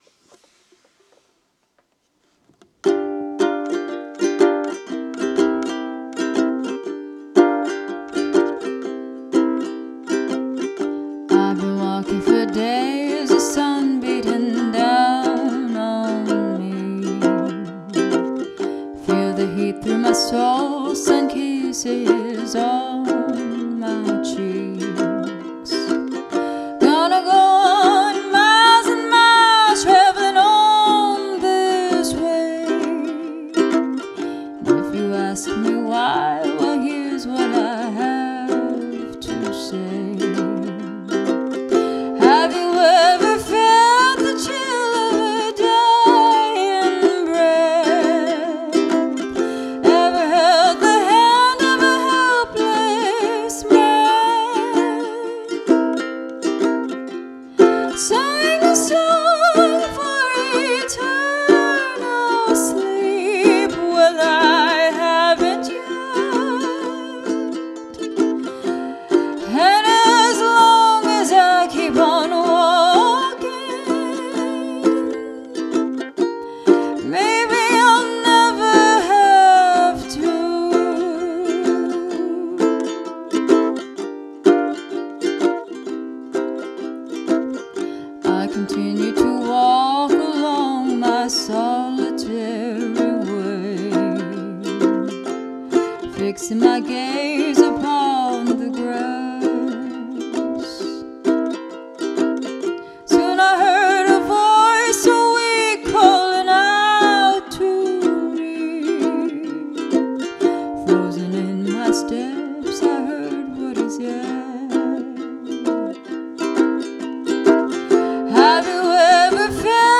(Acoustic Folk)